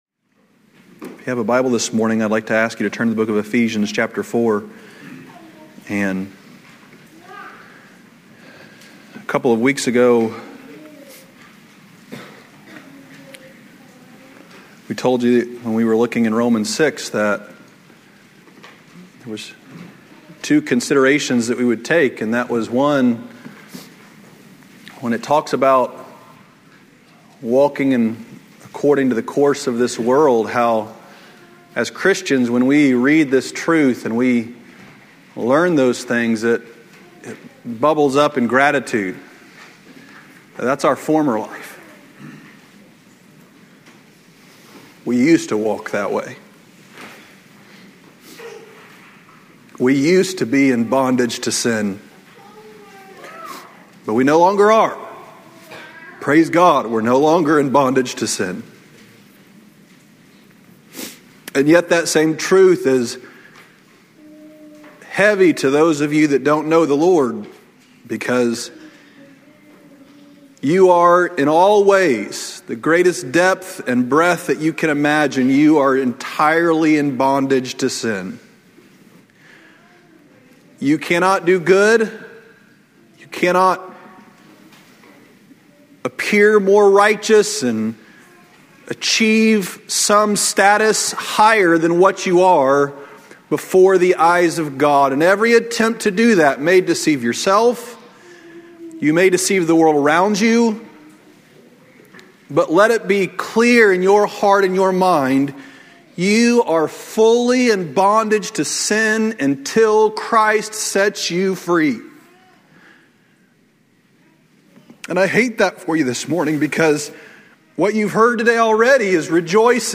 From Series: "Sunday Morning Messages"